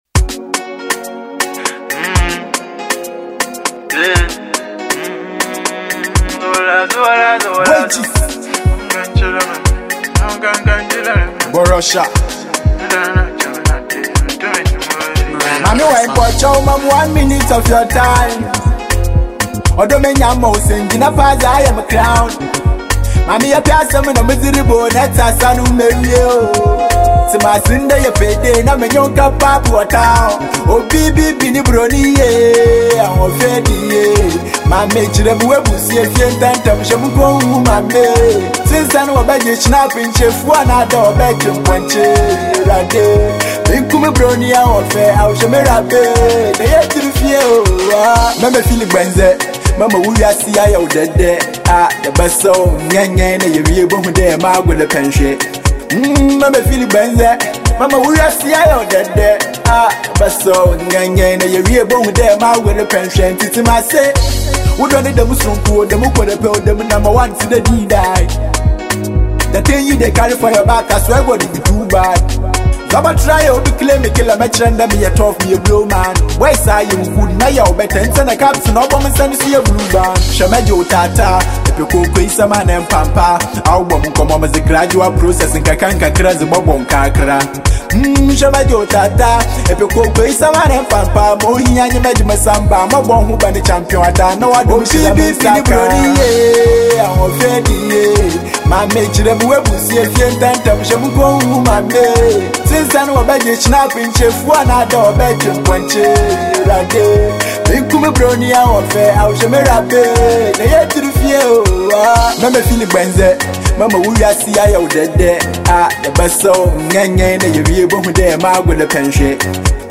Ghana Music Listen